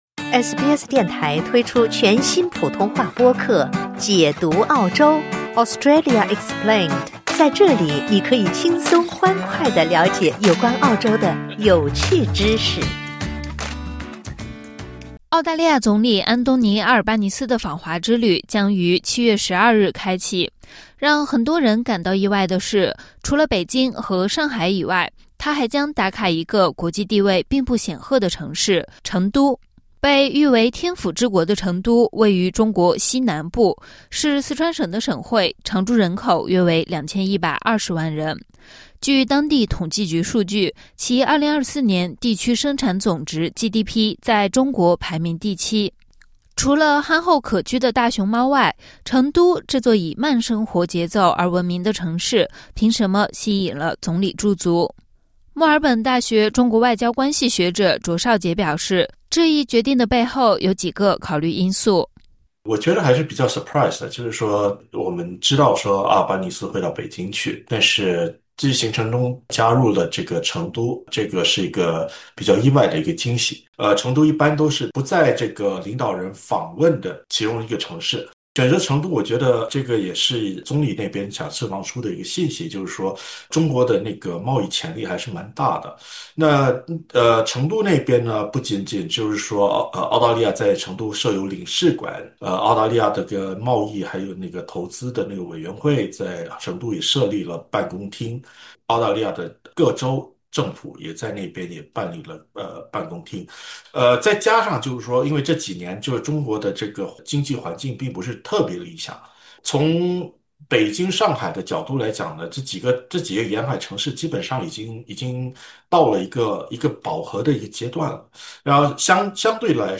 那么，对于本次两国领导人会晤，在澳华人反应如何？两国领导人可能会讨论哪些话题？SBS普通话采访了华人社区成员和两位澳中关系学者，请听采访内容。